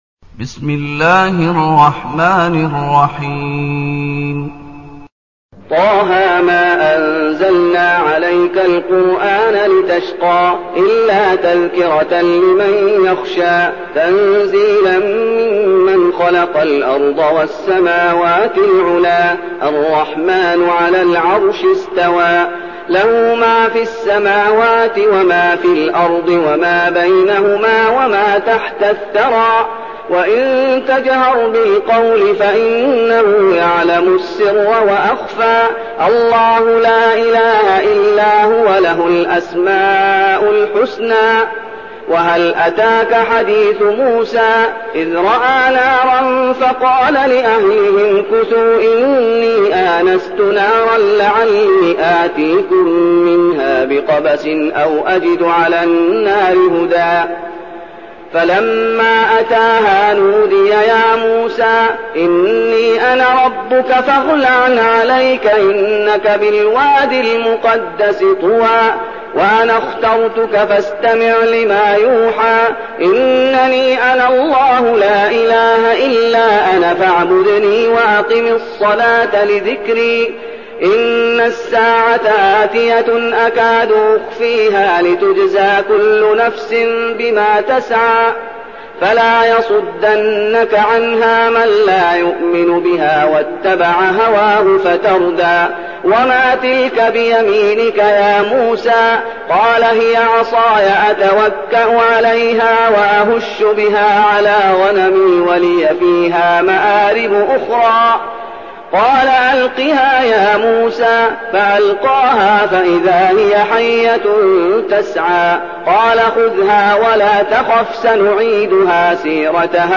تلاوة سورة طه بصوت الشيخ محمد أيوب
تاريخ النشر ١ محرم ١٤١٠ المكان: المسجد النبوي الشيخ: فضيلة الشيخ محمد أيوب فضيلة الشيخ محمد أيوب سورة طه